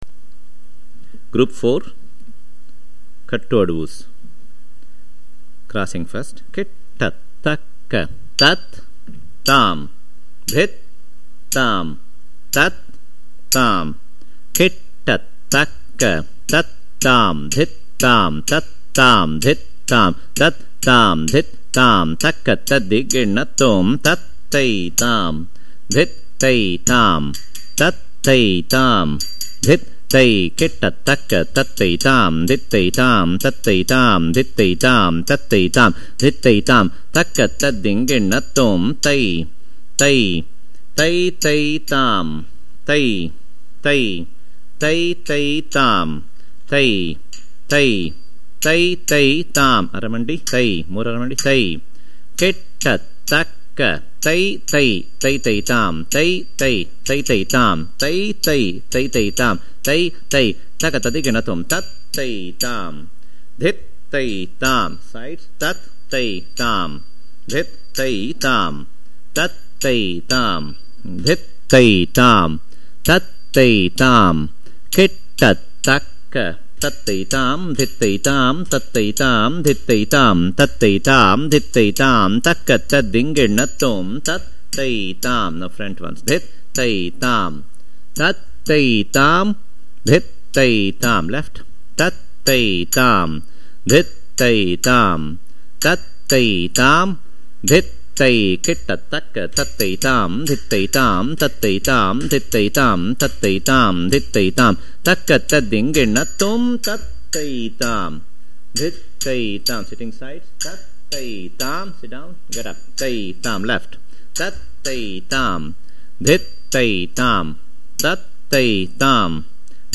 Adavu - fast - Group 4.mp3